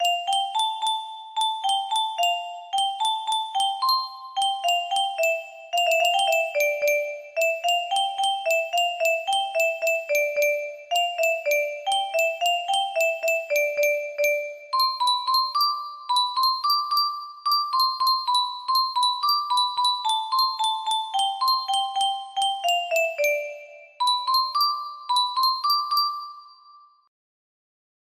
Tevekte Üzüm Kara music box melody